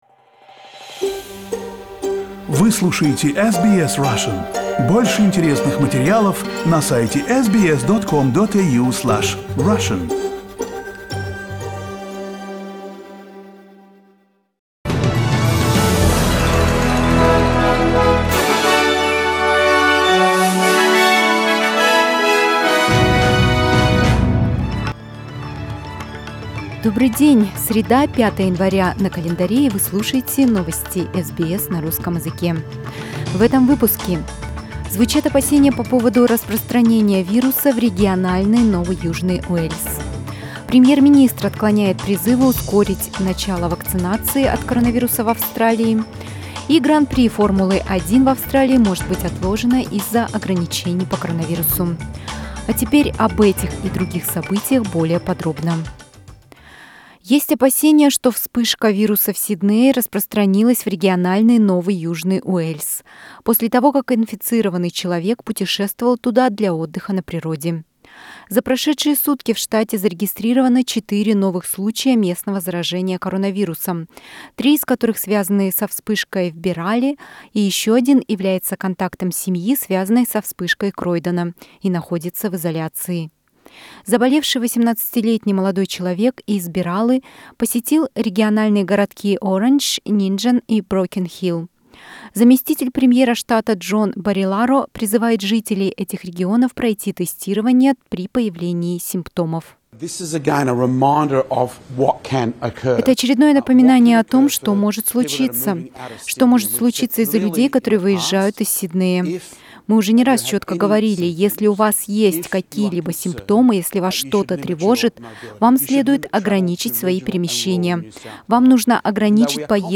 Новостной выпуск за 5 января